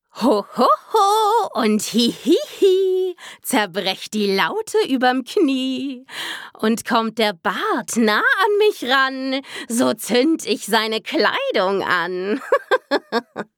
Charakter Bösewicht:
Stimmalter: ca. 18-35 Jahre